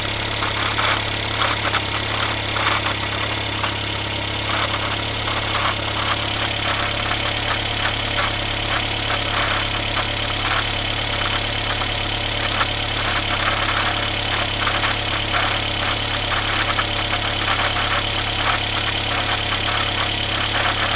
V převodovce vozu Felicia 1.3 bmm z roku 1996 je slyšet nápadné kovové drnčení.
První přiložený audio soubor obsahuje záznam zvuku pořízený mikrofonem v blízkosti víka převodovky.
Na té nahrávce jsou slyšet i ventily..Neškodilo by,kdyby se seřídily ventilové vůle..má to zvuk jak diesel *smich*
prevodovka.mp3